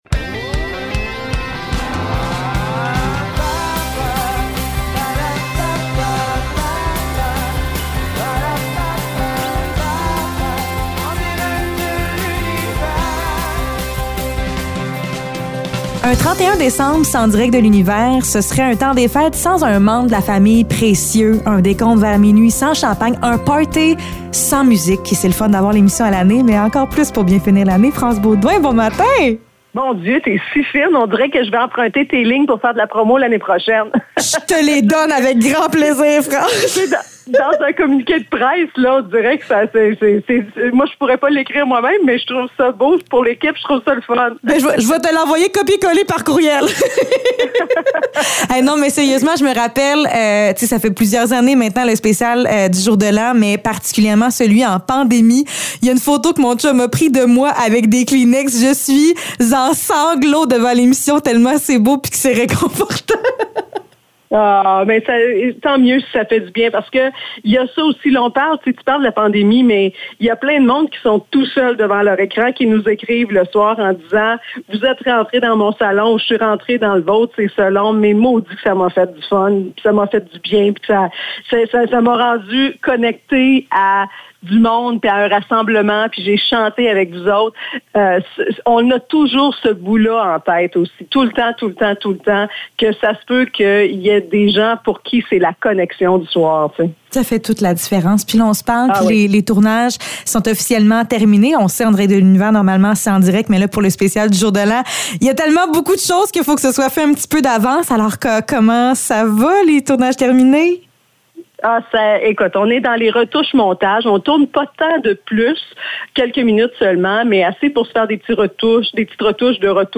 Entrevue avec France Beaudoin